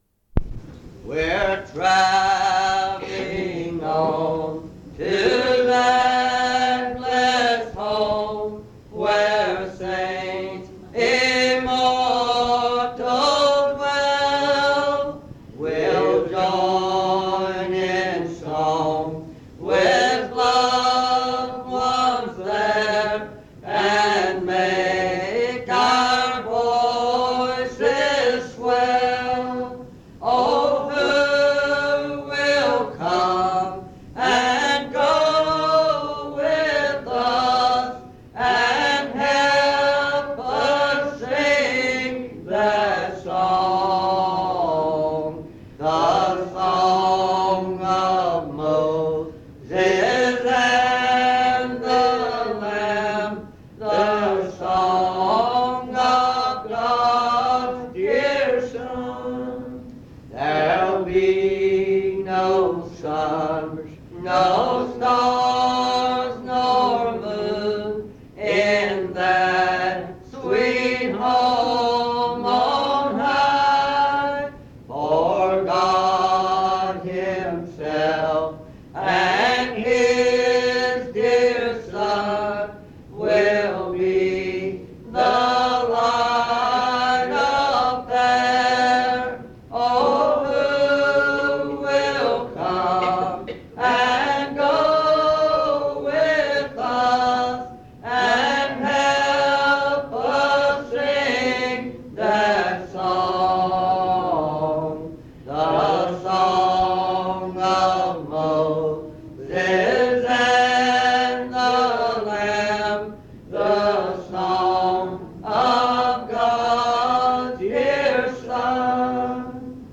Location Guilford County (N.C.) Browns Summit (N.C.)